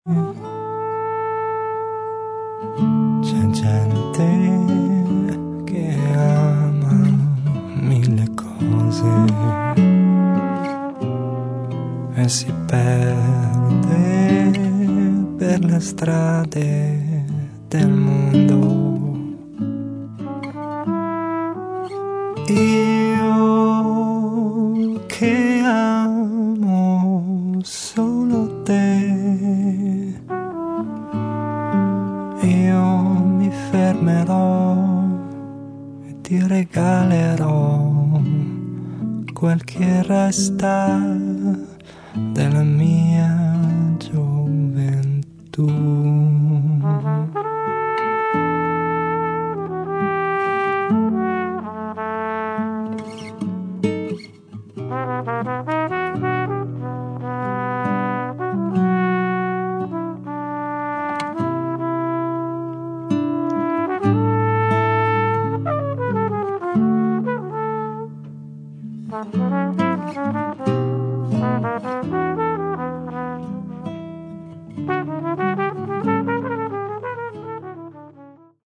registrato e missato alla
chitarre acustiche e classiche